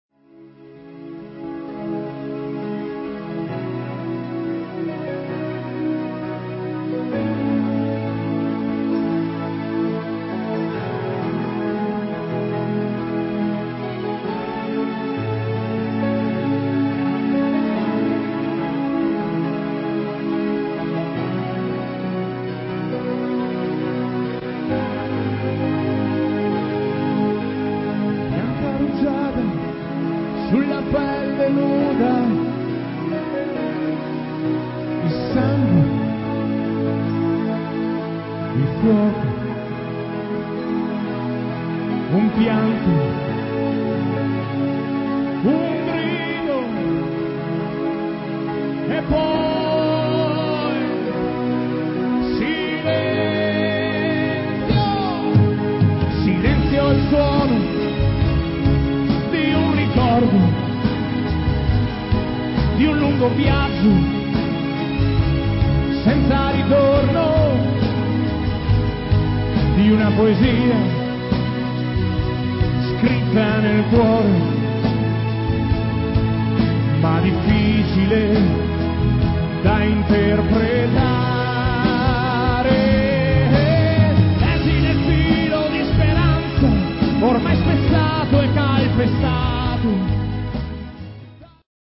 alla chitarra e alla voce
alla batteria
al basso
alle tastiere